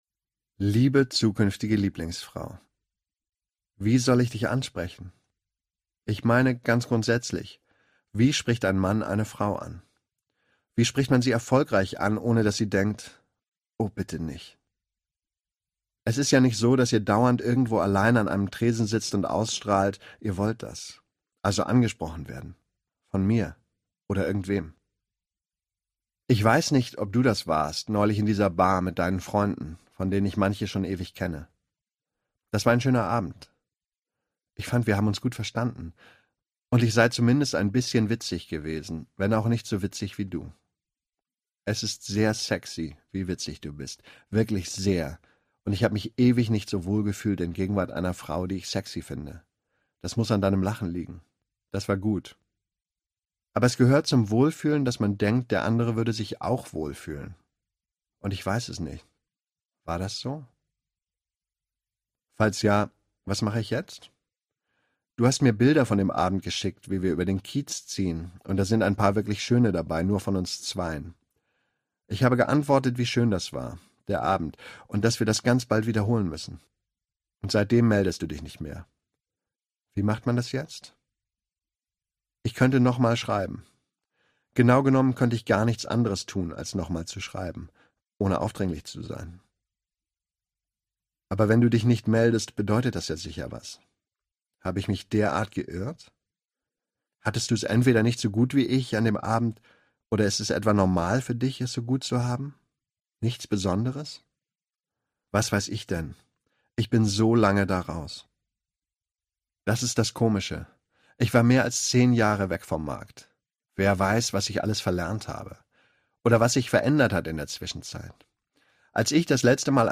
2018 | Ungekürzte Lesung